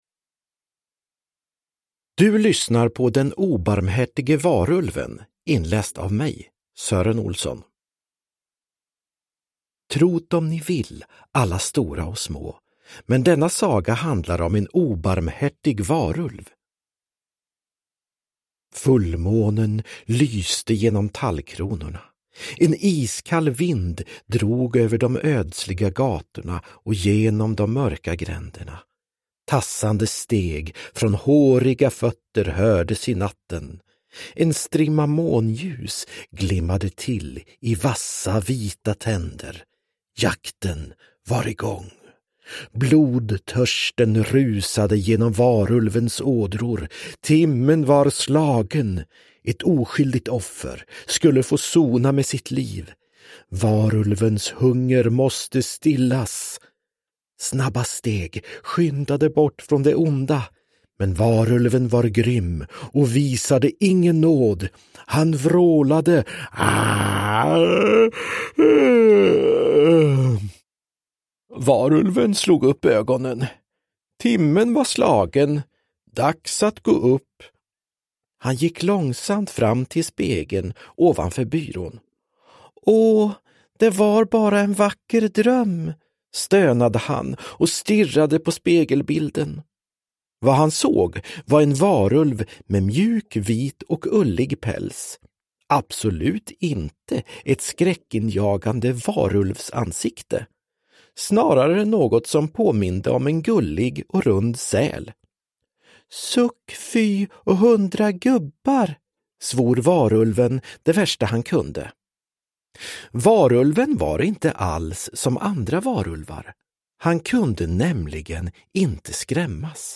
Den obarmhärtige varulven – Ljudbok – Laddas ner
Uppläsare: Sören Olsson, Anders Jacobsson